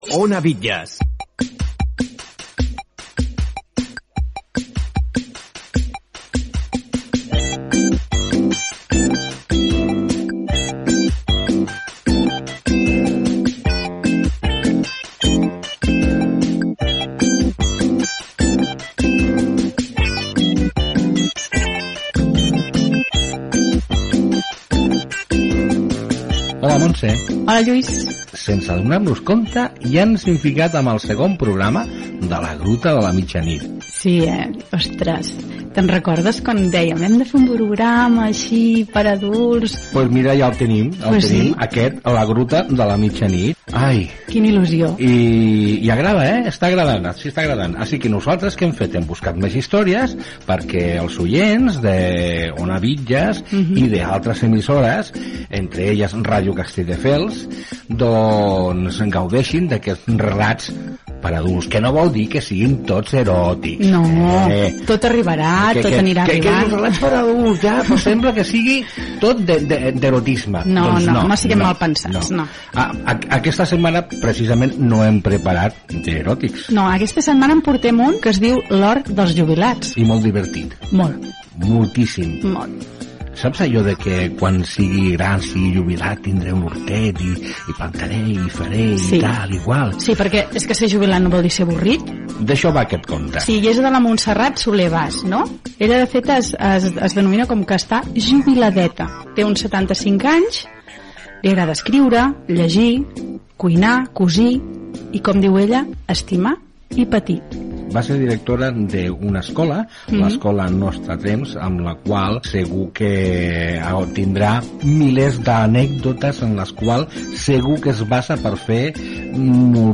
Identificació de l'emissora, sintonia, presentació del conte per a adults protagonista del programa: "L'hort dels jubilats"